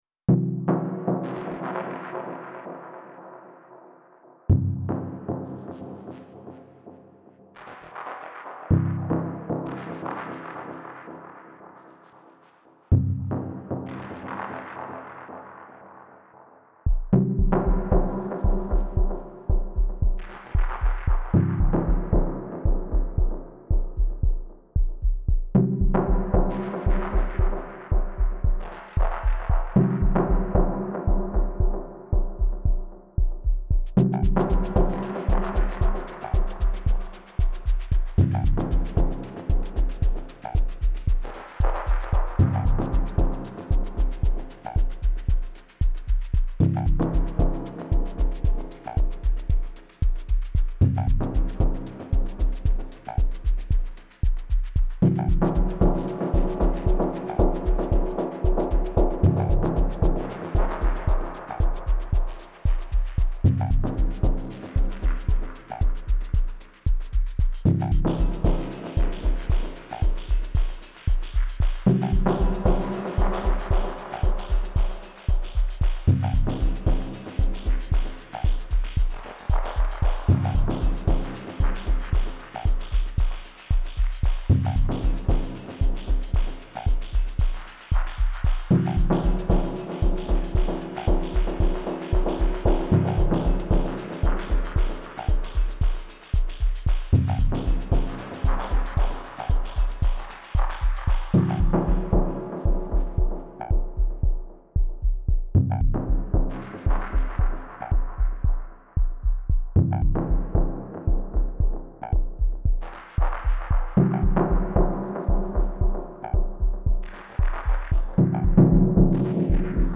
Here’s a live attempt at Basic Channel-flavored dub techno with the Sytakt chord machine.
SY Chord for the stabs, recorded straight from the Syntakt.
Nice stabs and filter action.